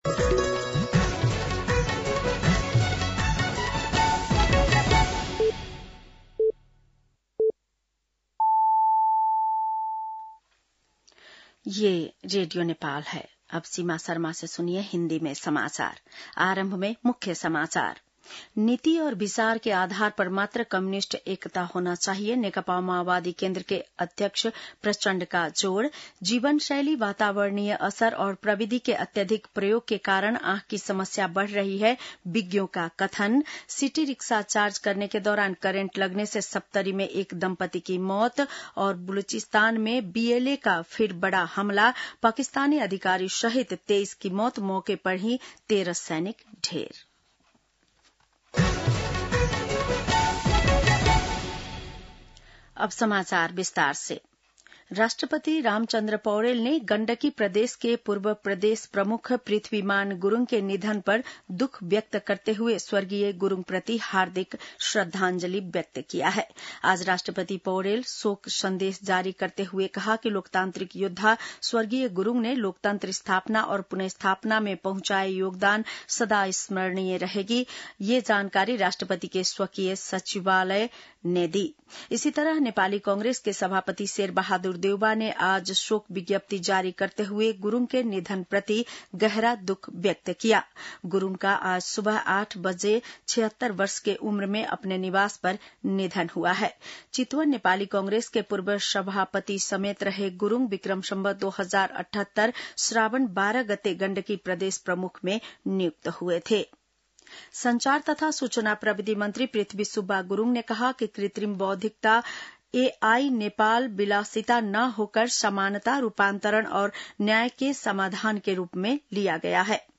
बेलुकी १० बजेको हिन्दी समाचार : १० साउन , २०८२